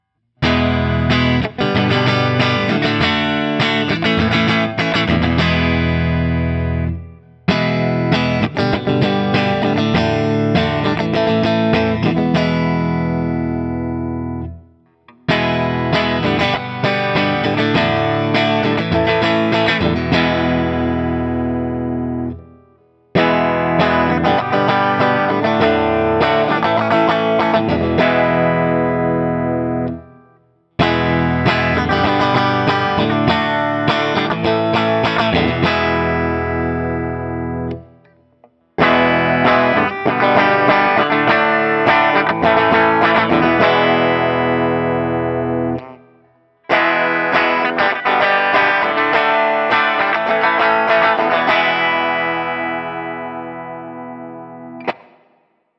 ODS-100
Open Chords #2
For these recordings I used my normal Axe-FX II XL+ setup through the QSC K12 speaker recorded direct via USB into my Macbook Pro using Audacity.
Thus, each recording has seven examples of the same riff.